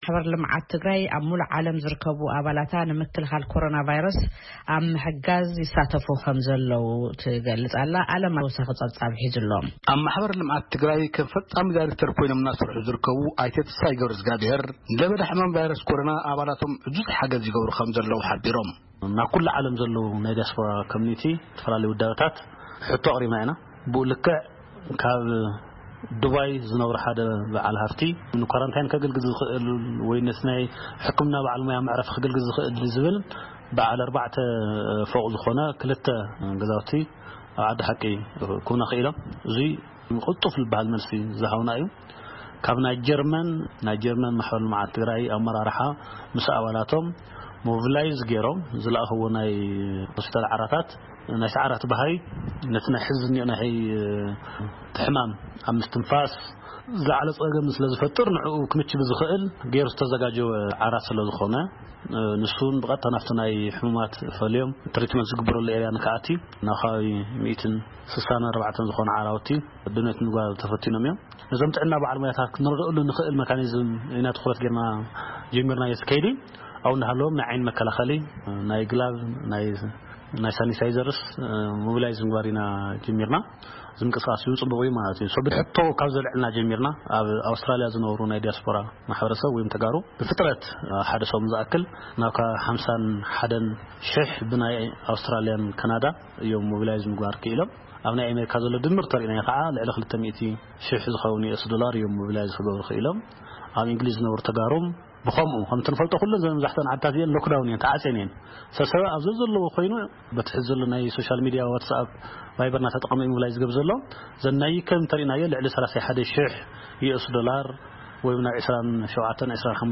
መቐለ —
ቃለ ምልልስ